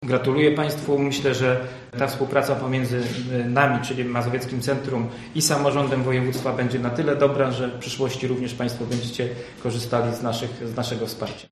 Dyrektor Mazowieckiego Centrum Polityki Społecznej w Warszawie, Aleksander Kornatowski dodaje, że pomoc potrzebującym jest bardzo istotna: